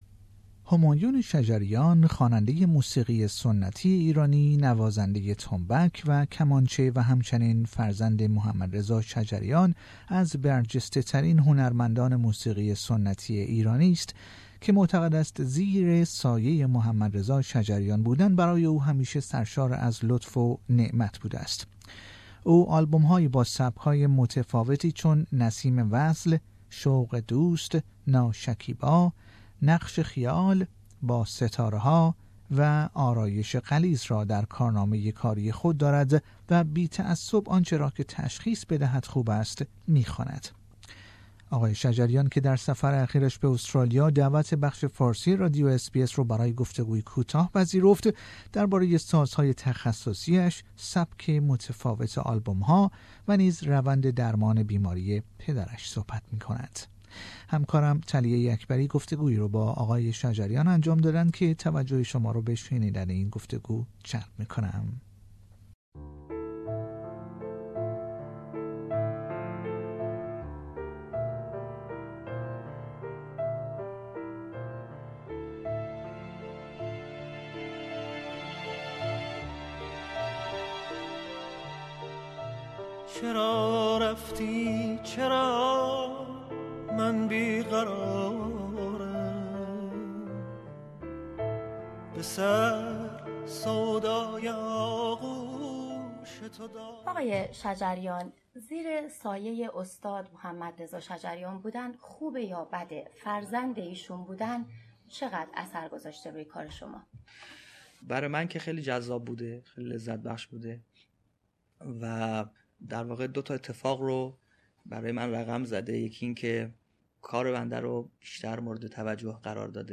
آقای شجریان در گفتگو با بخش فارسی رادیو اس بی اس درباره سازهای تخصصی اش، سبک متفاوت آلبوم ها و نیز روند درمان بیماری پدرش صحبت کرد.